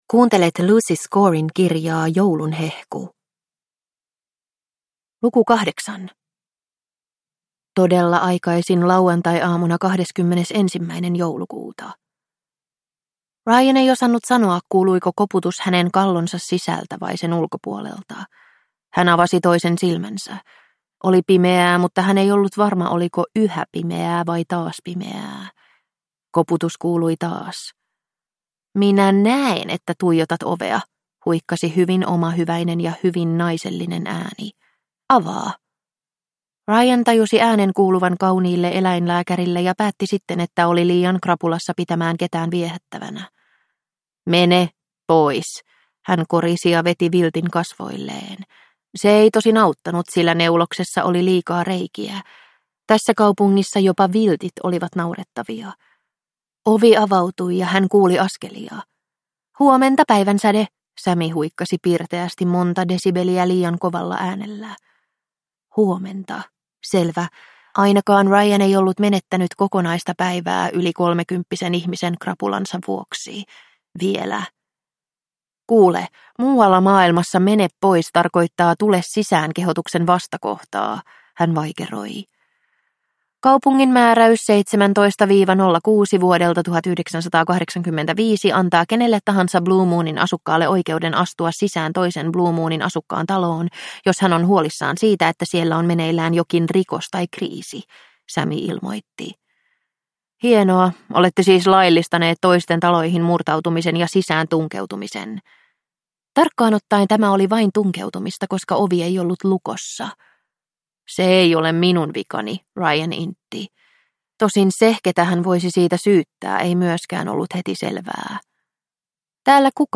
Joulun hehku - Luukku 7 – Ljudbok